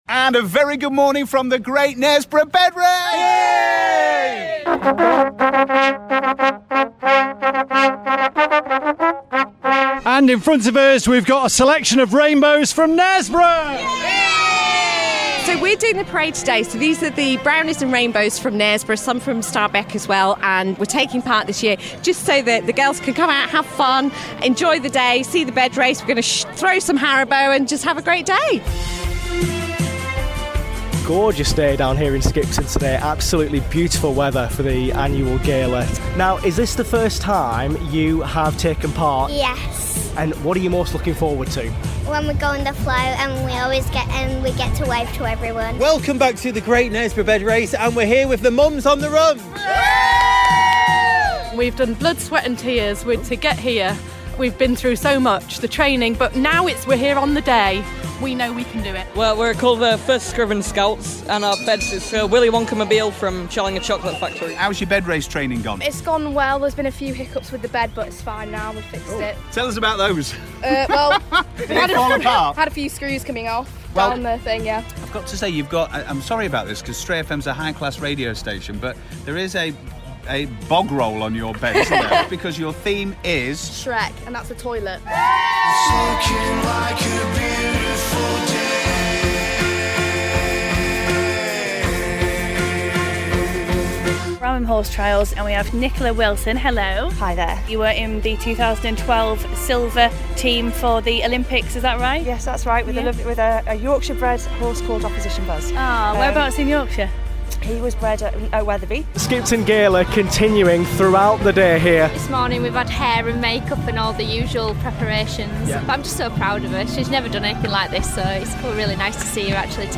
On Saturday 9th June, Stray FM were at the great Knaresborough bed race, Skipton Gala and tractor fest at Newby Hall and Gardens.